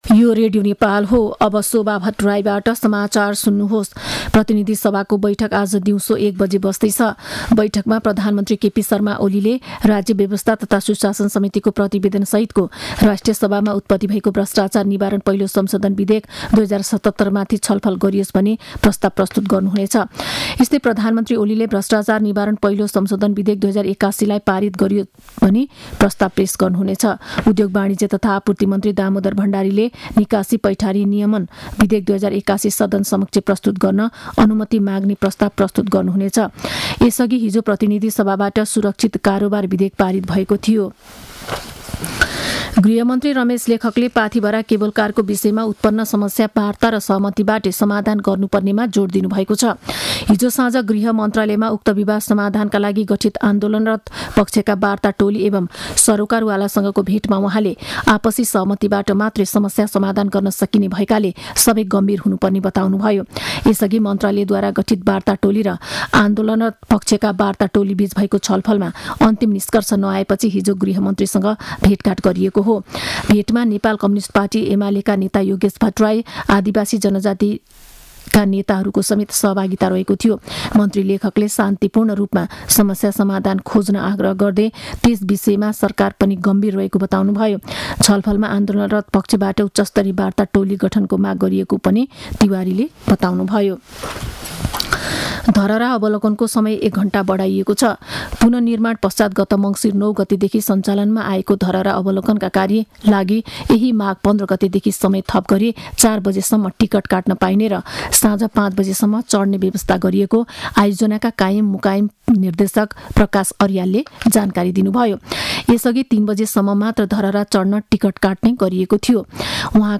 मध्यान्ह १२ बजेको नेपाली समाचार : ३० माघ , २०८१